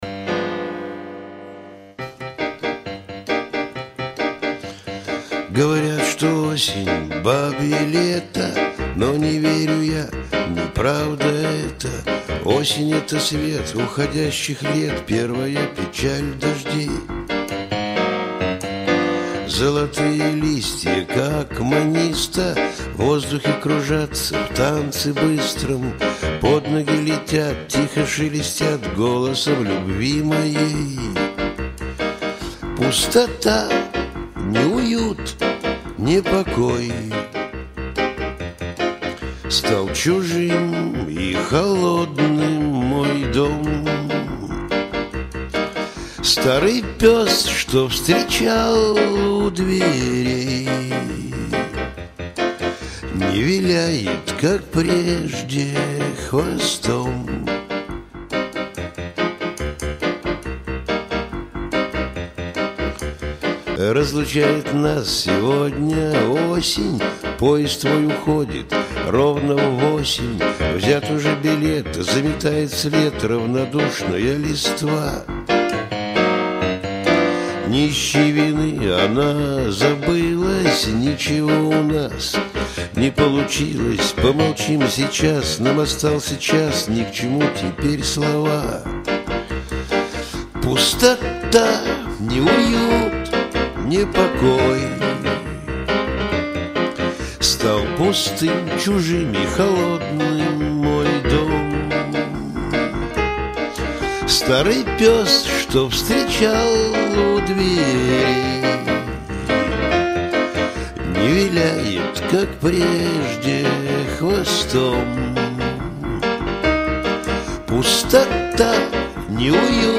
Исполнение Автором На Радио